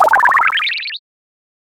Cri de Flotillon dans Pokémon HOME.